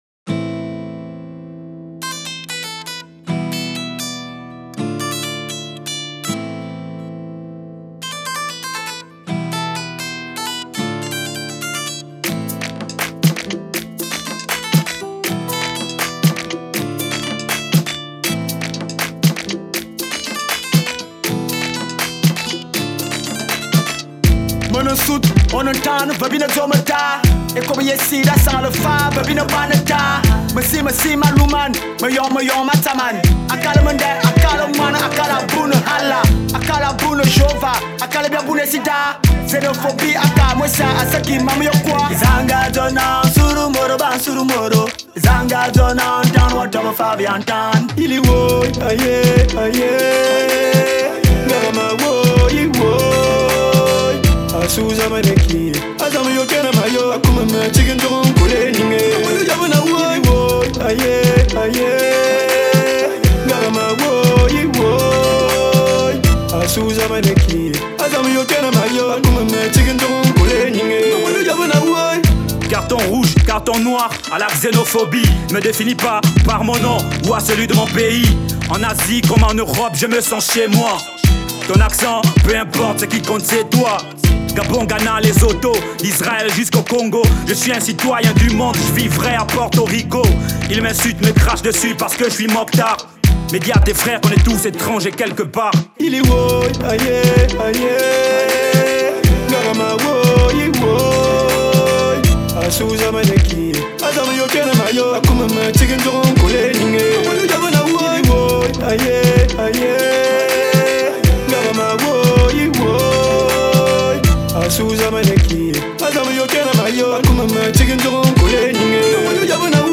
Genre : HipHop